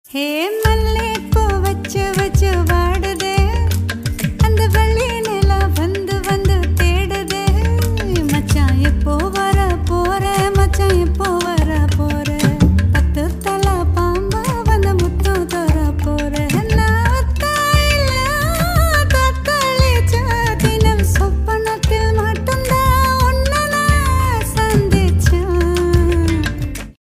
Nice melody